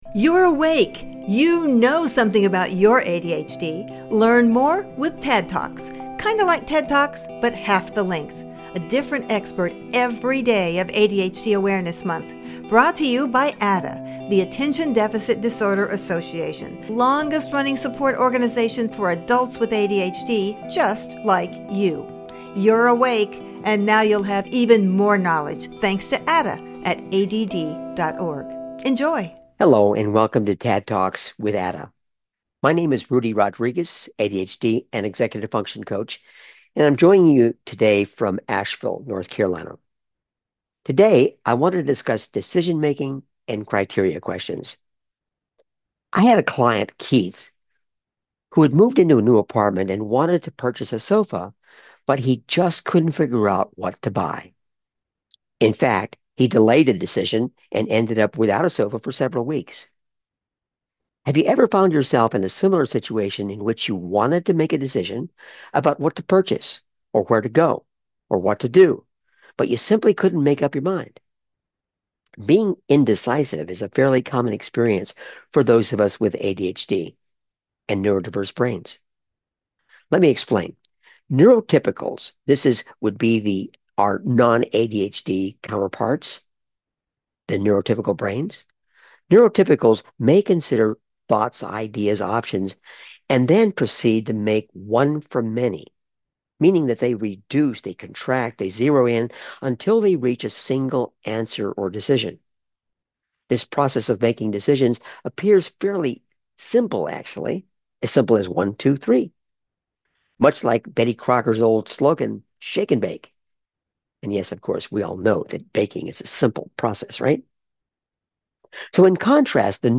TADD TALK